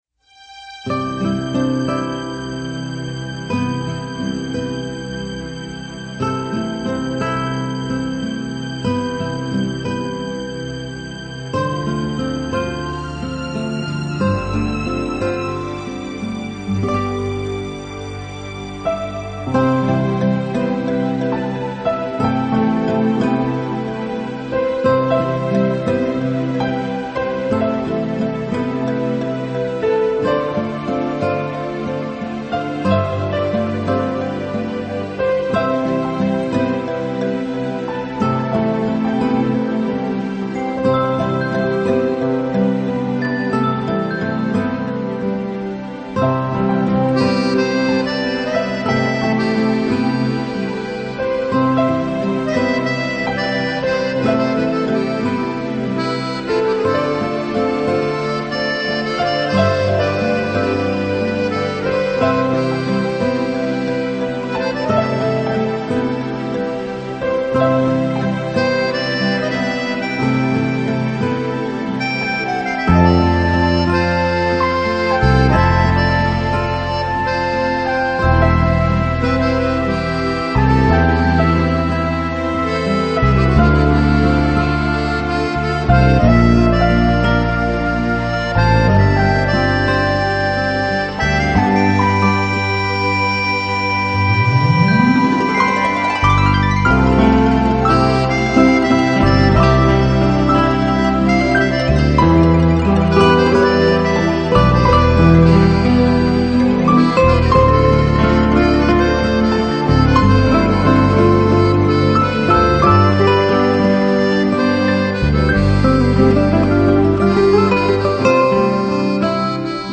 Complete 10 CD Collection Of Relaxation Music